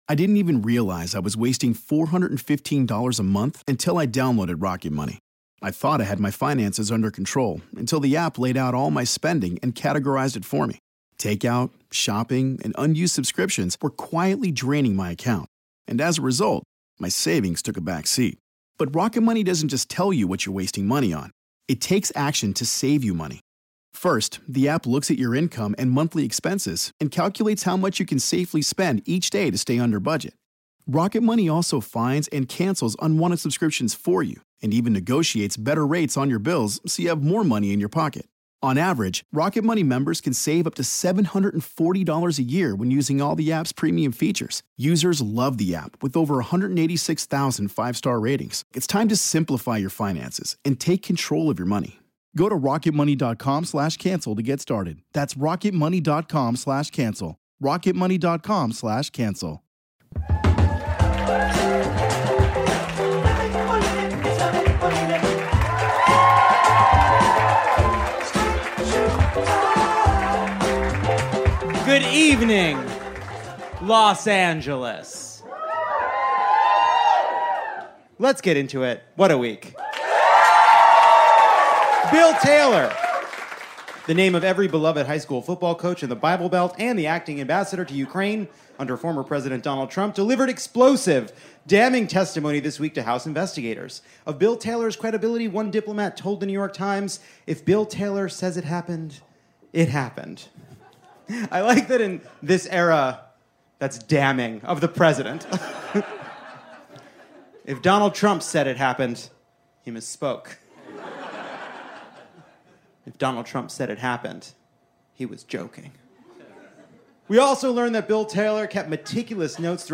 Broad City's Abbi Jacobson joins to unpack the sexist advice offered at the accounting firm Ernst and Young.